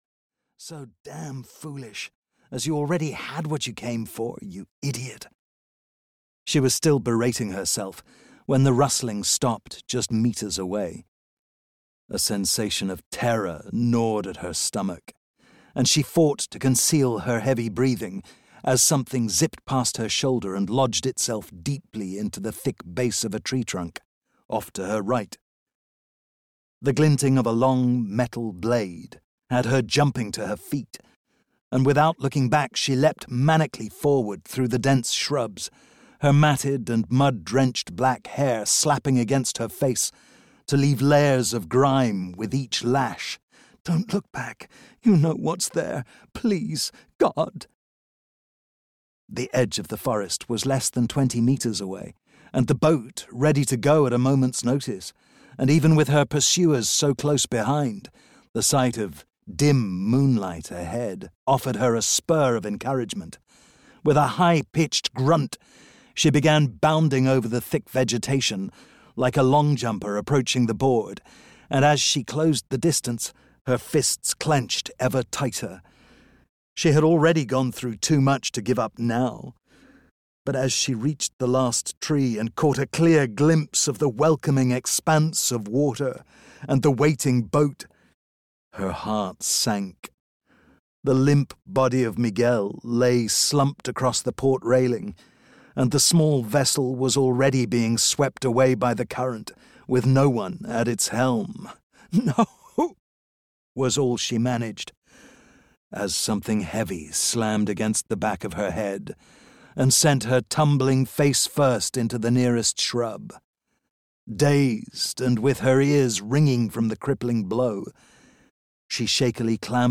The Shadow Conspiracy (EN) audiokniha
Ukázka z knihy